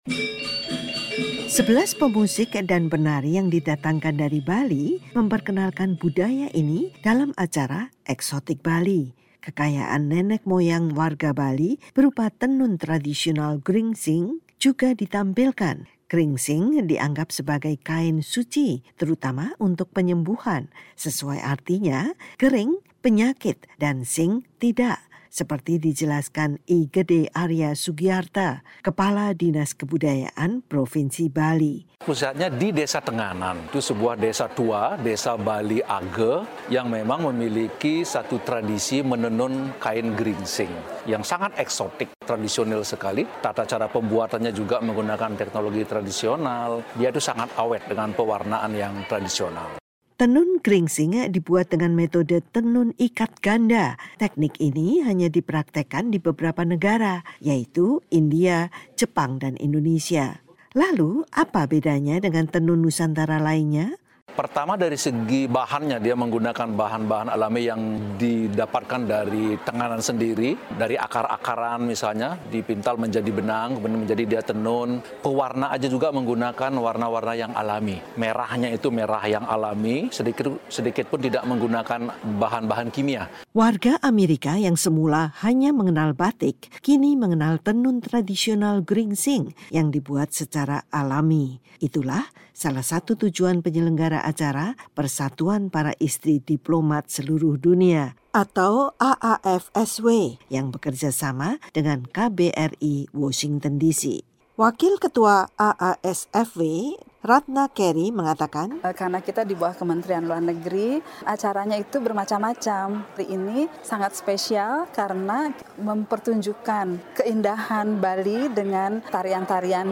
Gamelan dan tarian Bali membahana di auditorium Departemen Luar Negeri Amerika.